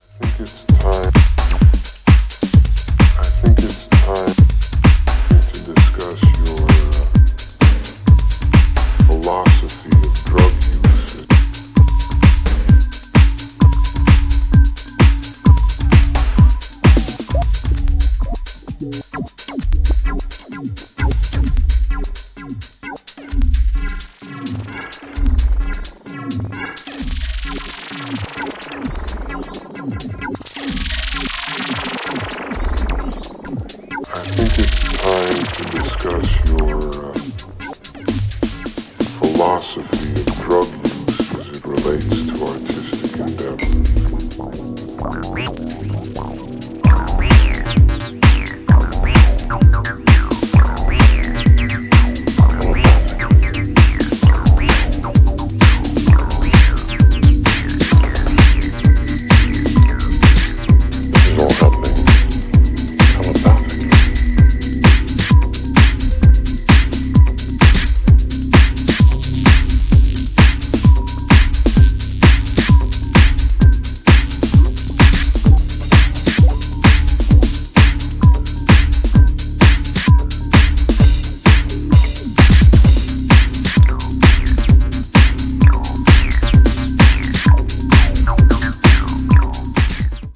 程よくアシッド/エフェクト/ヴォイス・サンプルを配し展開をつけながら、グルーヴィーなミニマル・ハウスを披露。